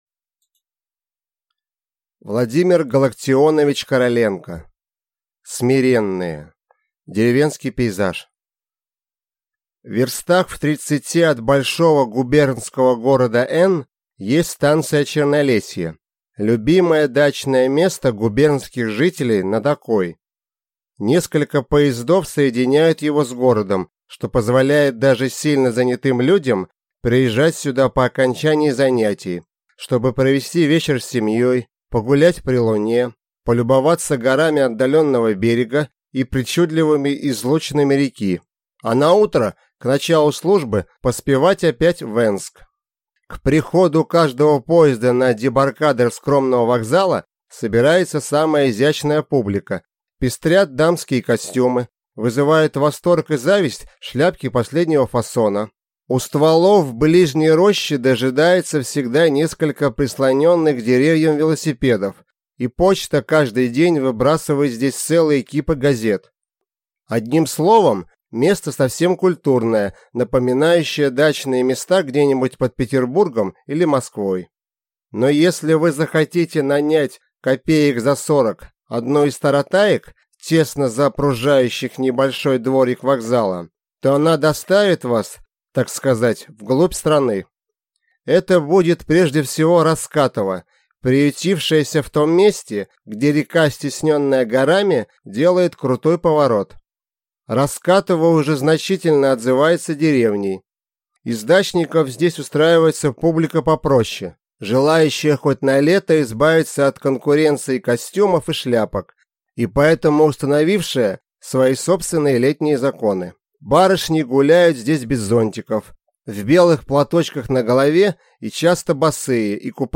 Аудиокнига Смиренные | Библиотека аудиокниг
Прослушать и бесплатно скачать фрагмент аудиокниги